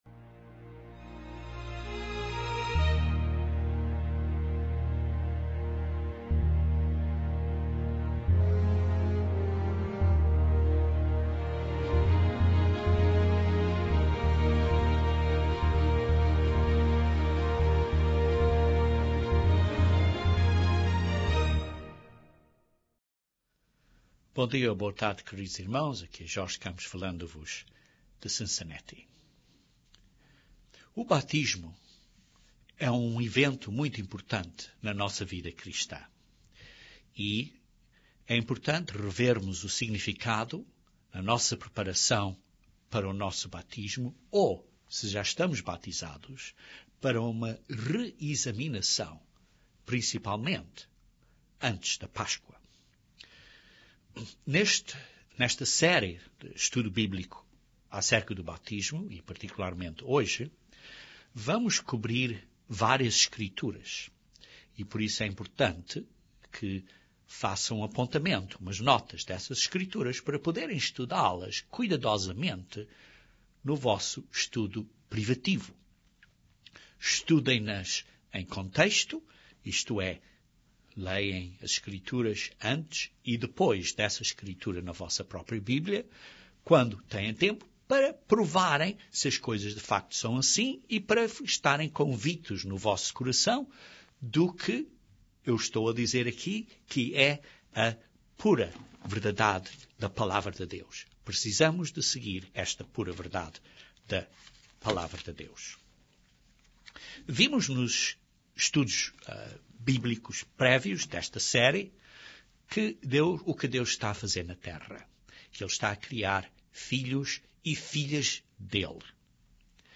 Este terceiro e último sermão nesta séria descreve a importância do arrependimento e do nosso compromisso que fazemos durante o batismo.